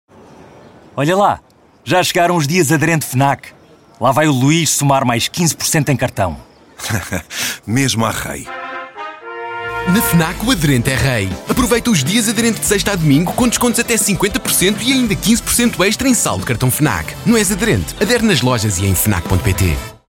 foram criadas duas abordagens de spot de rádio: uma versão institucional, focada no conceito da campanha: